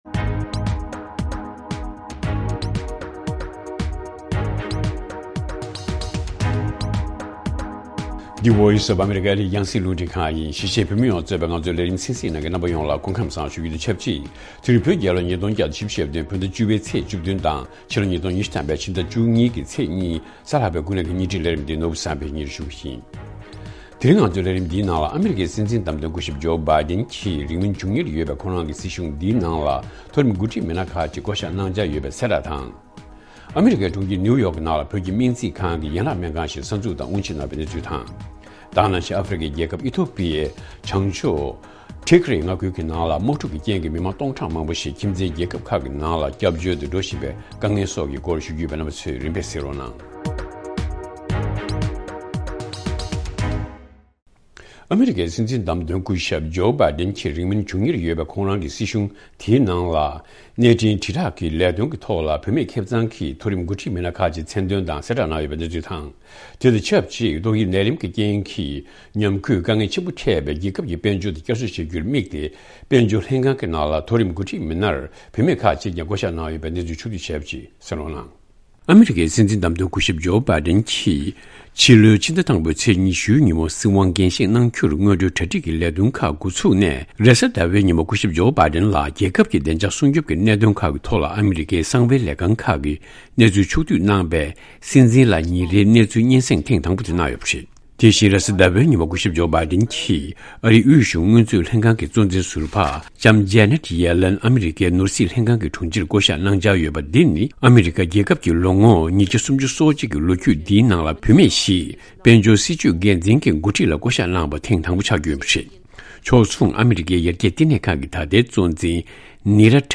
ཐེངས་འདིའི་ཀུན་གླེང་དུ་སྲིད་སྐྱོང་འོས་མིར་ལངས་མཁན་ཨཅཱརྱ་ཡེ་ཤེས་ཕུན་ཚོགས་ལ་བཅར་འདྲི་ཞུས་པ་དང་། འཛམ་གླིང་སྟེང་གི་སྐད་གྲགས་ཆེ་བའི་གྷི་རེ་མེ་གཟེངས་རྟགས་ལ་མིང་འདོན་བྱས་པའི་བསྟན་འཛིན་ཆོས་རྒྱལ་ལགས་ཀྱི་གླུ་དབྱངས་བར་དོ་ཞེས་པའི་ཐད་བགྲོ་གླེང་བྱས་ཡོད།།